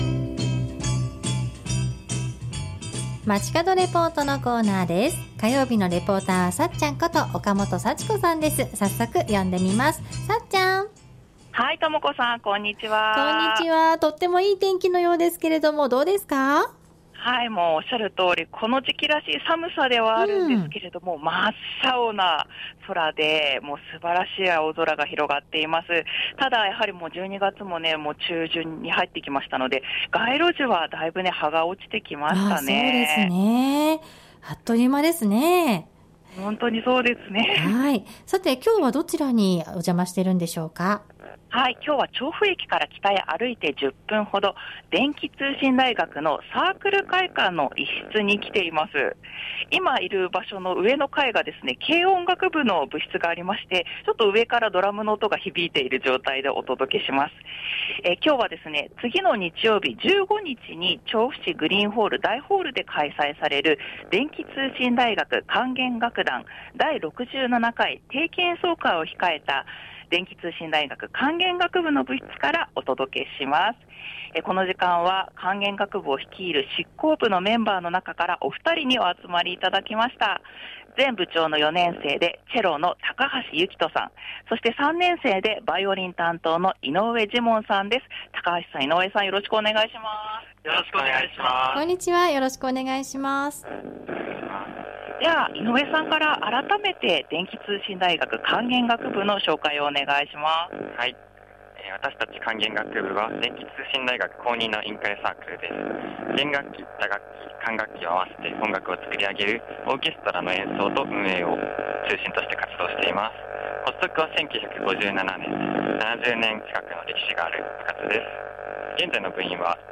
中継は電気通信大学にお邪魔してサークル会館の一室にある管弦楽部の部室から 今週末、12月15日（日）に調布市グリーンホール・大ホールで開催される「電気通信大学 管弦楽団 第67回定期演奏会」についてご紹介しました。
合唱団の皆さんとの練習風景 ドイツ語で歌唱されます 放送では今回の定期演奏会の内容や見どころ、演奏会にかける思いをお2人に伺った他、合唱団と行った「第九」の練習の模様も特別にお送りしています。